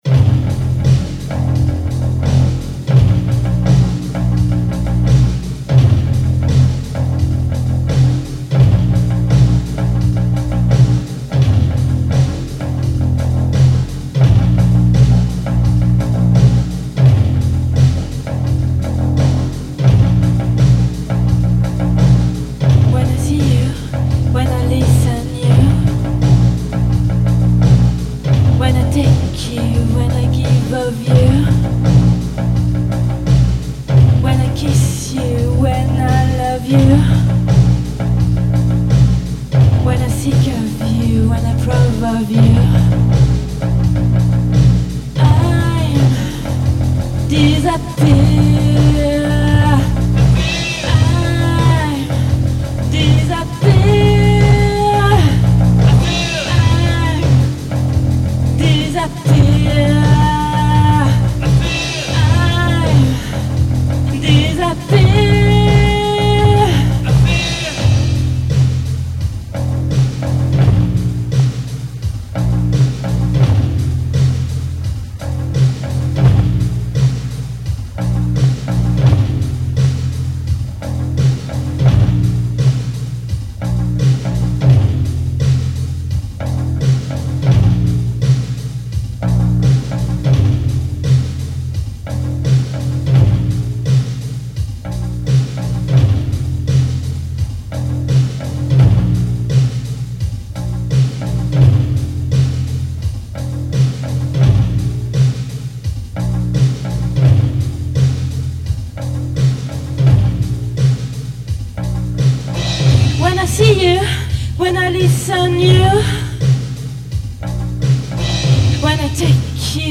vocals
drums
both playing bass